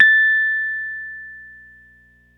RHODES CL0LR.wav